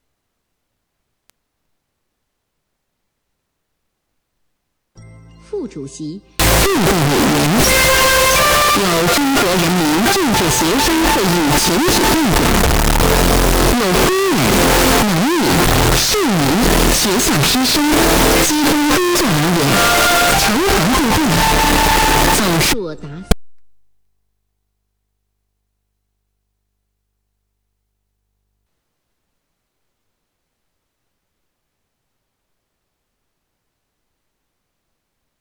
PCM1690: start up white noise
Customers using PCM1690 often experience; After changing the following configuration, the probability decreases and only exists at the moment of startup, duration of about 30 seconds;
PCM1694--startup-white-noise.wav